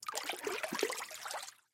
На этой странице собраны звуки весла: плеск воды, ритмичные гребки и другие умиротворяющие аудиоэффекты.
Водим веслом по воде слышен звук